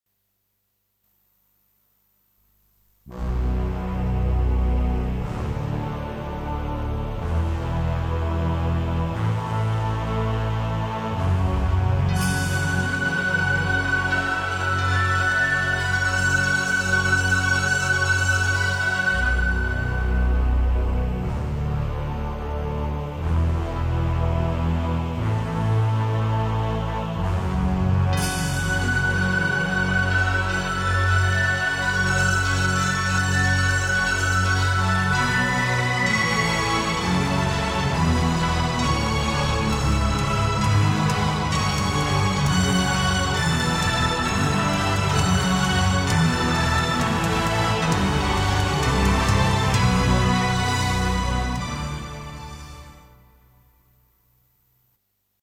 Opera Referewnce CD